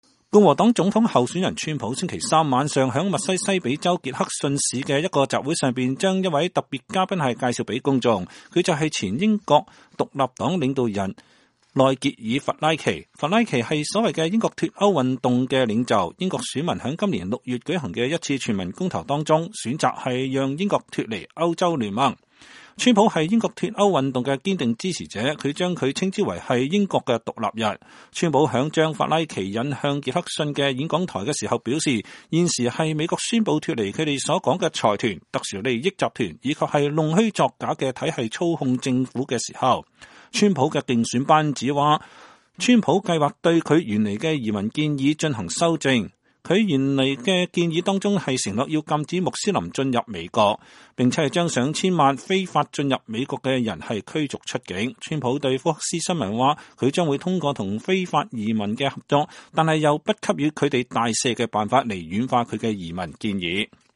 英國脫歐運動領袖在川普競選集會上講話